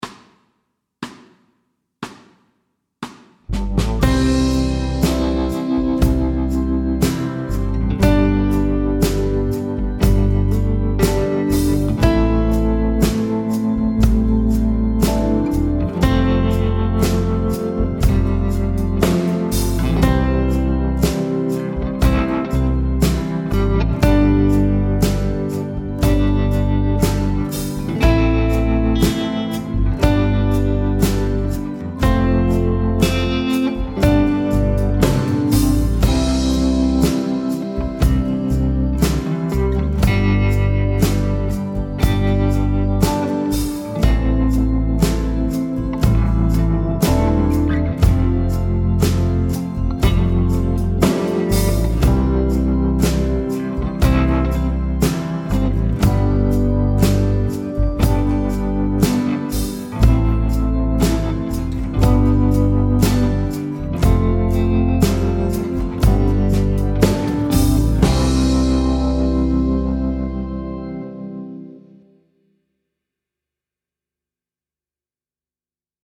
Slow C instr (demo)
I dette afsnit skal du læse og spille 3 toner: C, D og E.
Rytmeværdier: 1/1- og 1/2 noder.